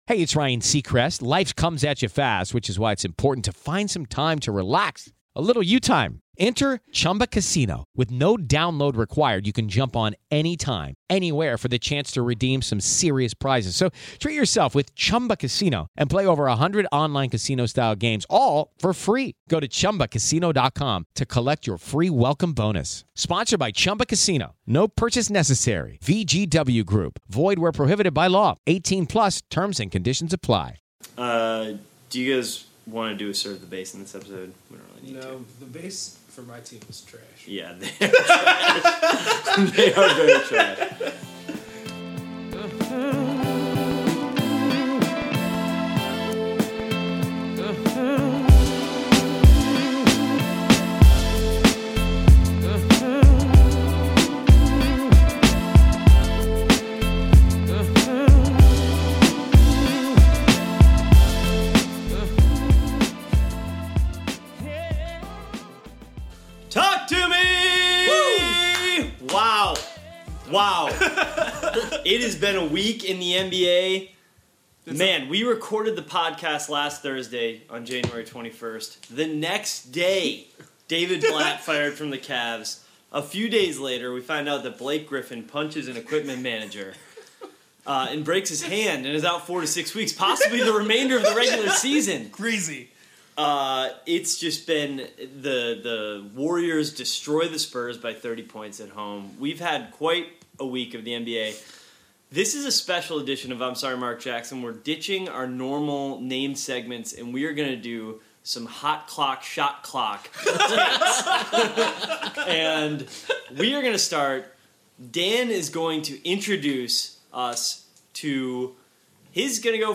Subtitle Three of the NBA's biggest fans race the clock to cook up some hot cakes and hot takes.